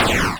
Shoot2.wav